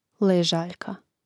lèžāljka ležaljka